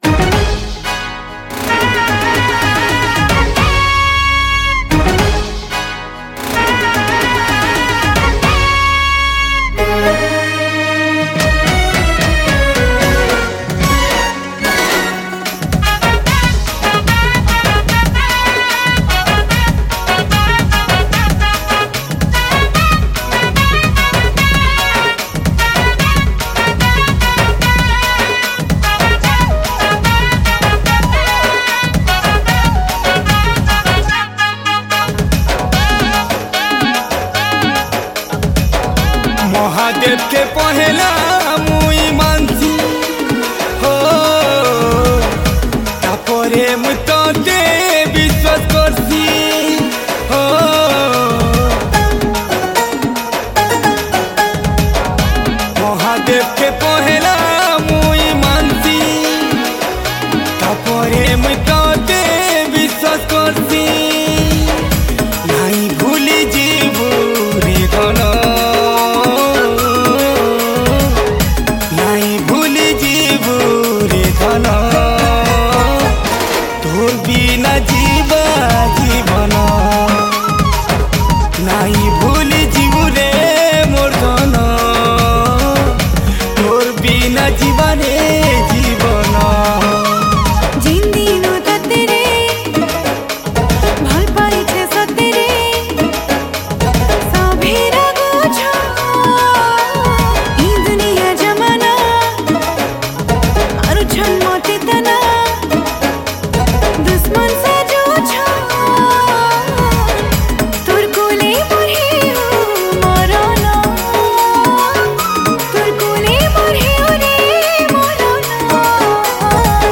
Category: New Sambalpuri